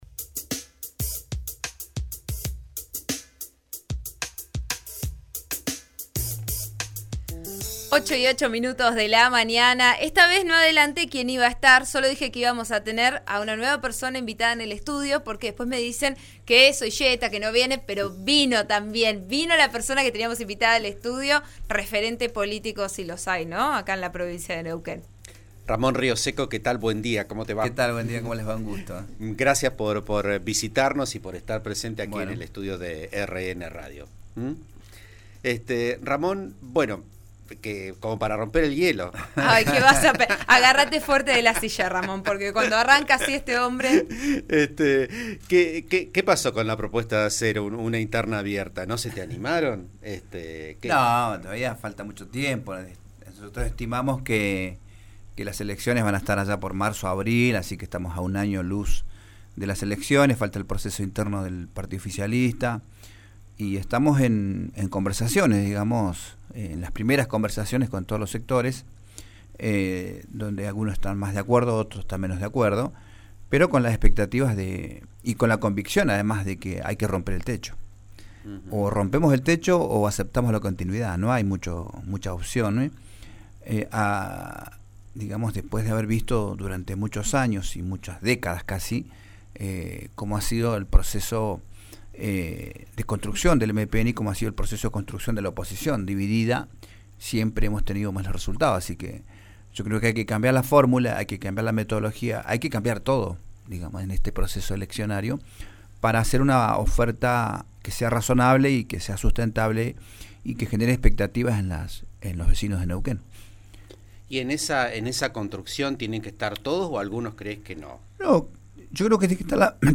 En una entrevista exclusiva con RN Radio, el dirigente político y varias veces candidato a gobernador, habló sobre la necesidad de ampliar la base de la coparticipación, volver a la industrialización en origen y opinó sobre la interna del Frente de Todos.